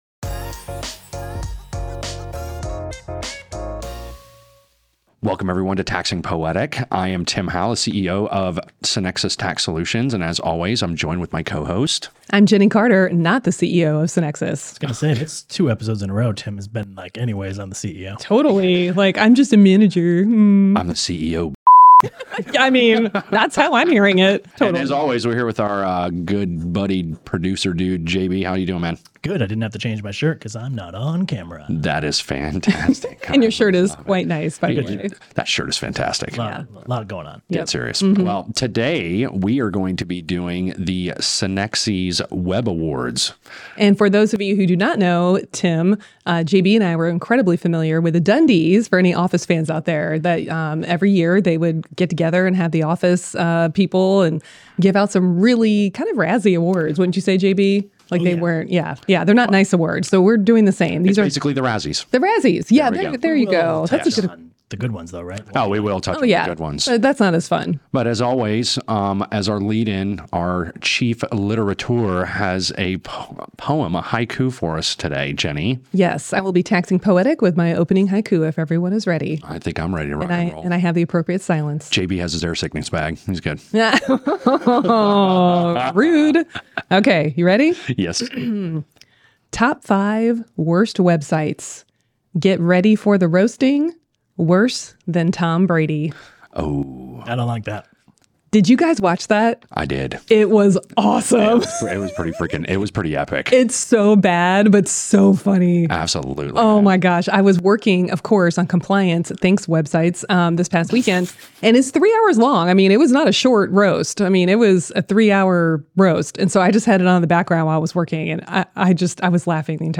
Tune in as they humorously roast and toast state compliance websites, ranking the top five best and worst.